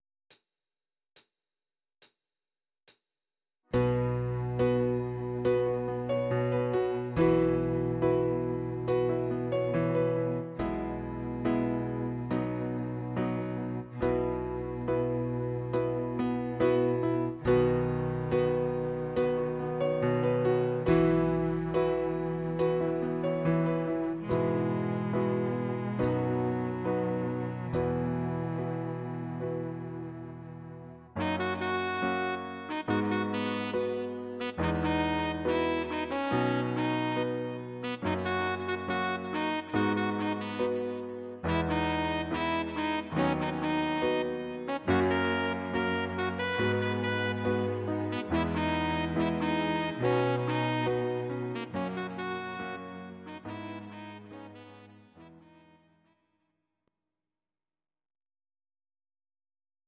Audio Recordings based on Midi-files
Rock, German